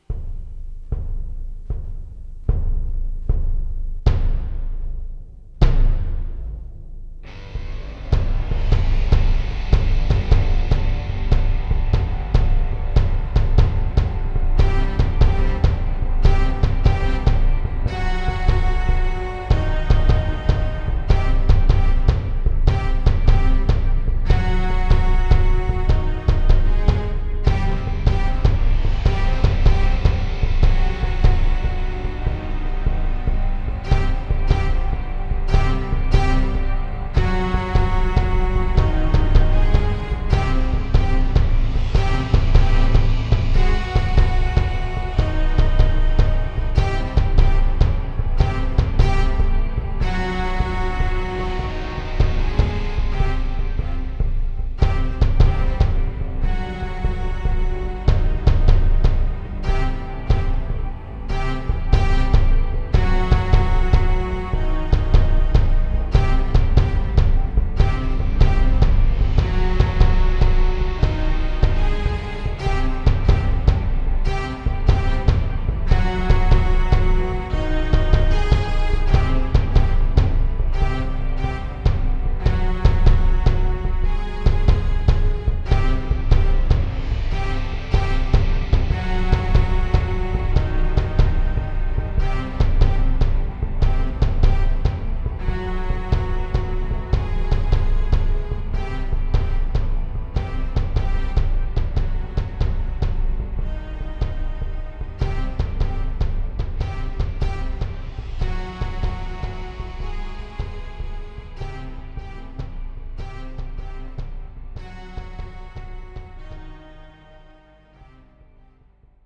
Pulsation Soundtrack with Strings.